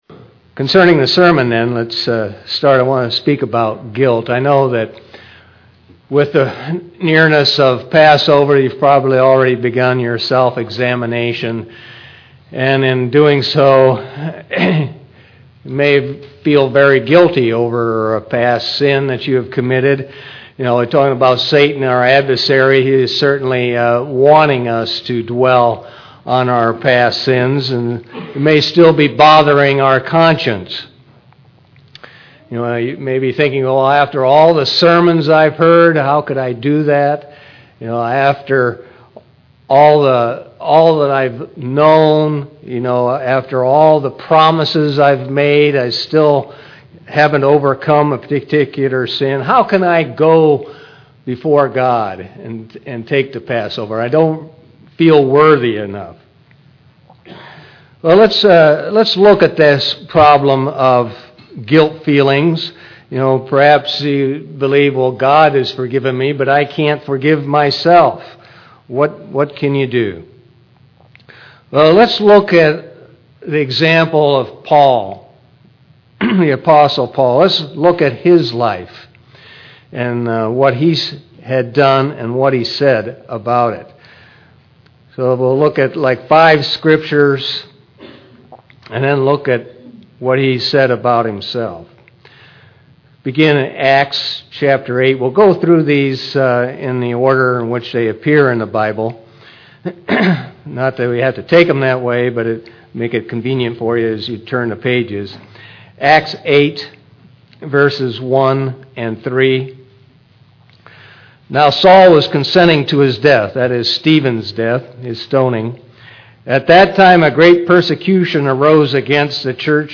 Some Christians continue to be burdened with guilt feelings after repentance and baptism. This sermon shows why and how to counter those thoughts and move on.